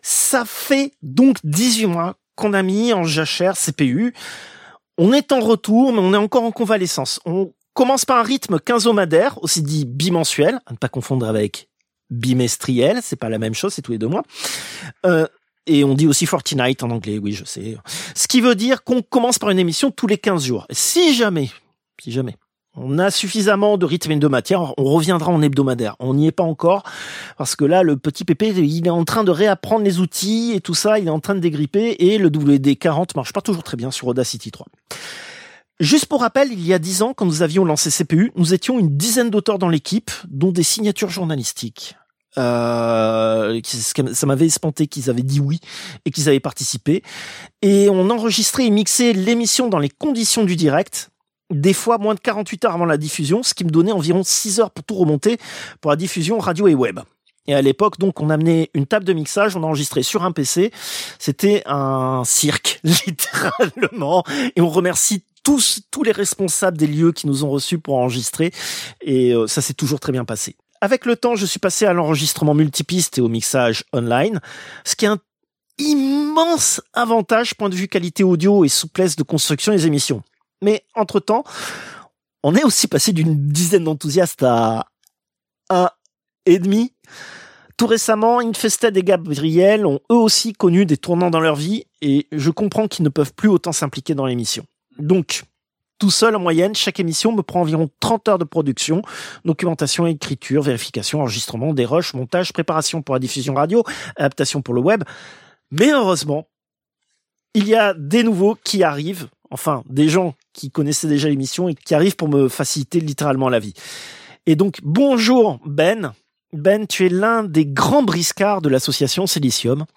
Extrait de l'émission CPU release Ex0222 : lost + found (rentrée 2025) seconde partie).
[Transcription partielle, des segments sont purement improvisés]